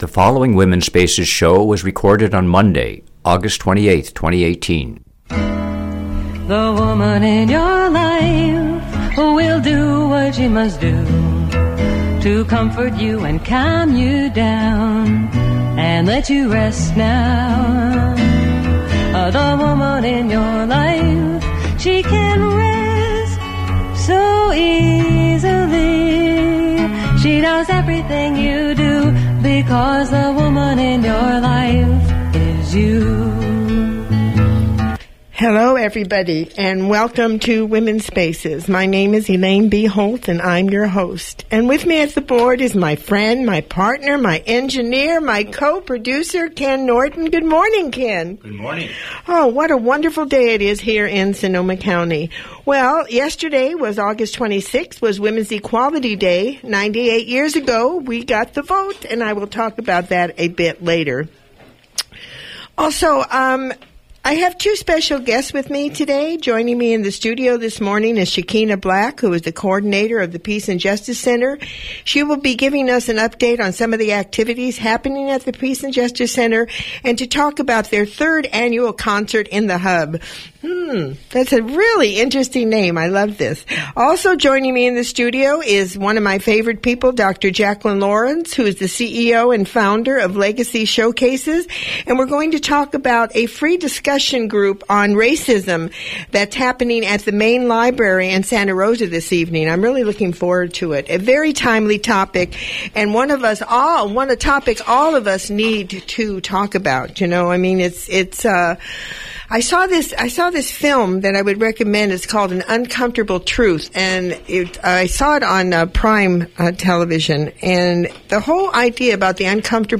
broadcast via KBBF-FM 89 on 8/27/2018
Original Radio Show ID: WSA180827Mp3 Player